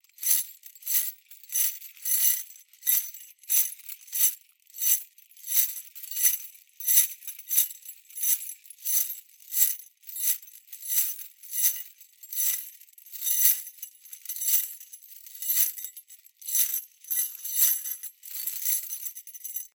Звуки кандалов
На этой странице собраны звуки кандалов: звон цепей, скрежет металла, тяжелые шаги в оковах.